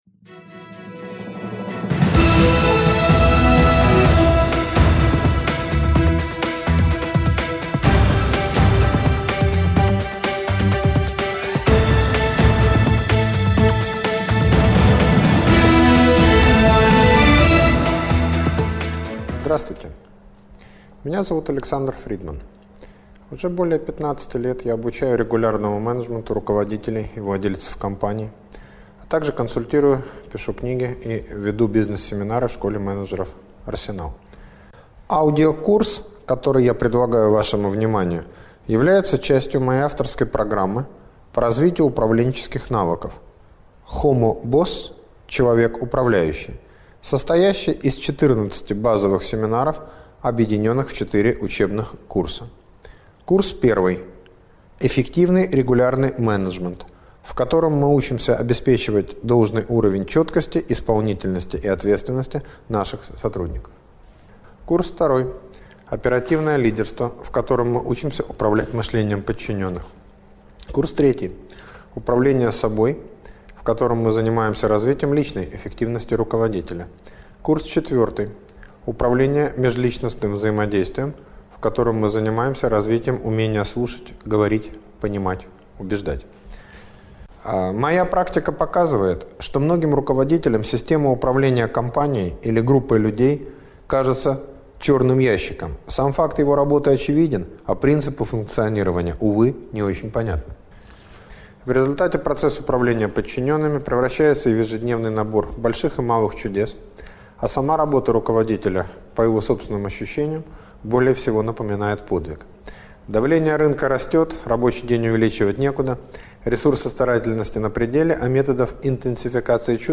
Управление мышлением подчиненных: (аудиокурс)